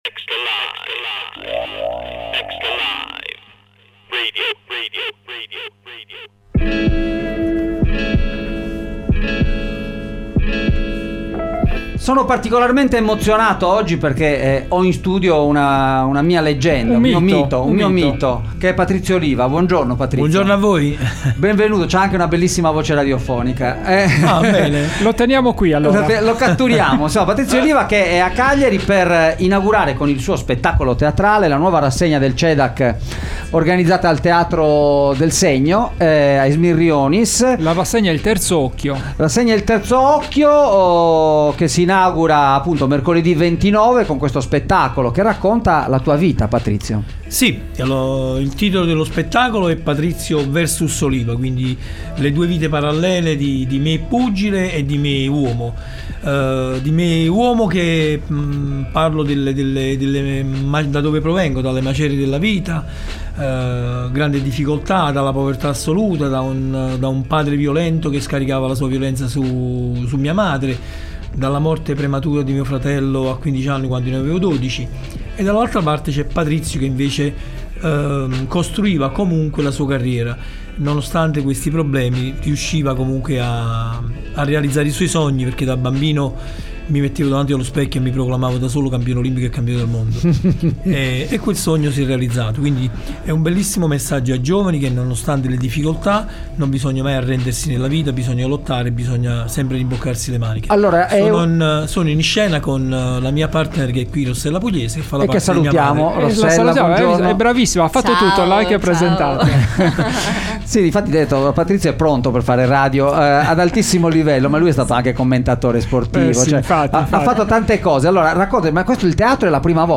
oliva_intervista2901.mp3